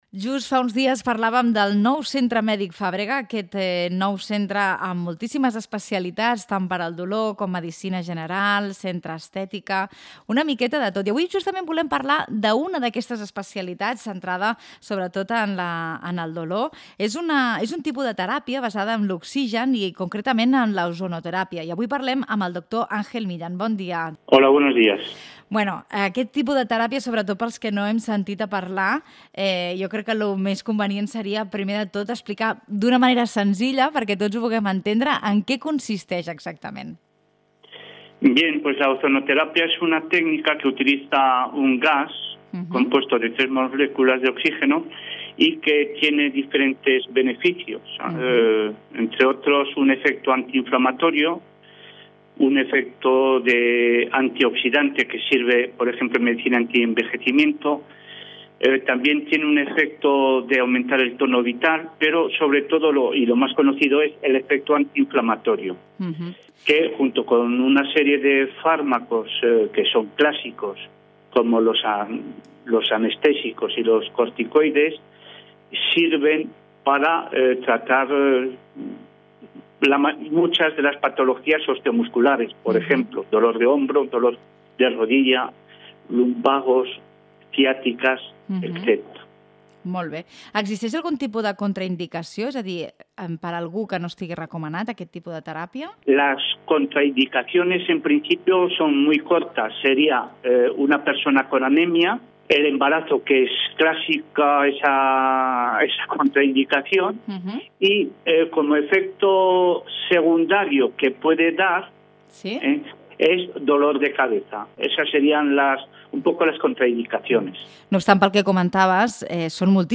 Al programa De Bon Matí hem parlat amb el Centre Mèdic Fàbrega sobre l’ozonoteràpia